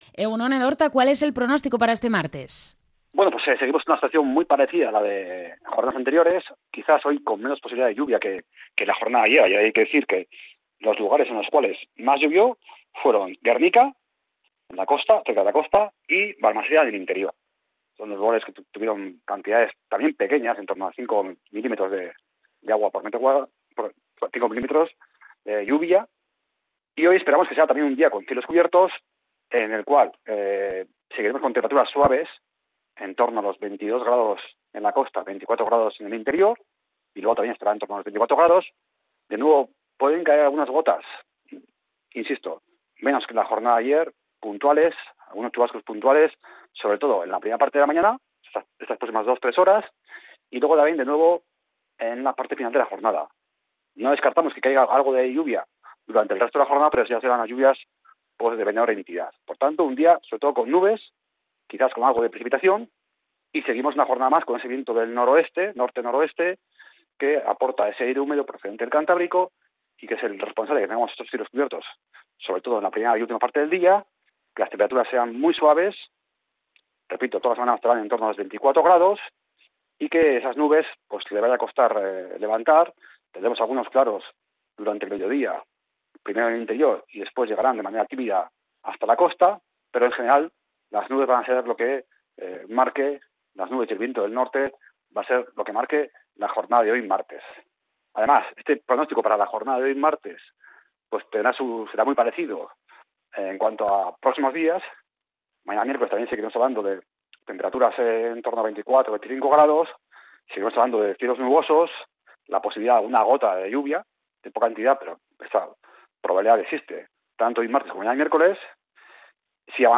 El pronóstico del tiempo para este 29 de julio